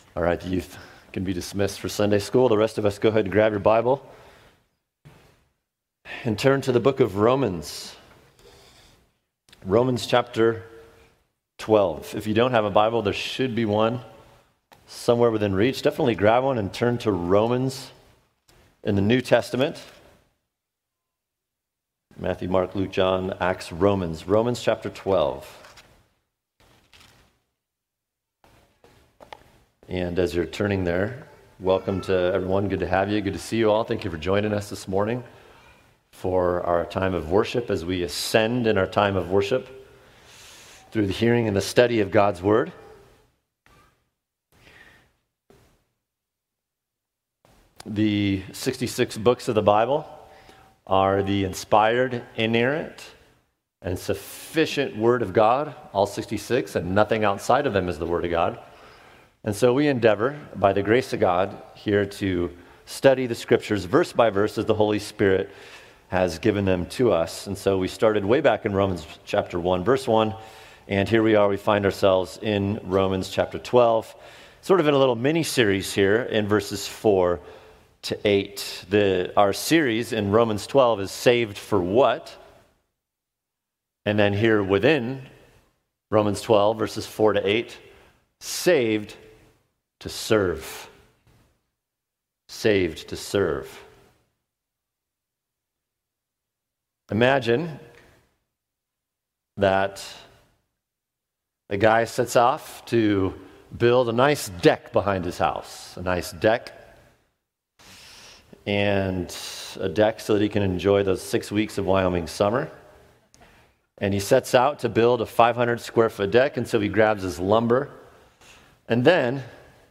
[sermon] Saved to Serve (Part 3): The Permanent Edification Gifts Romans 12:6-8 | Cornerstone Church - Jackson Hole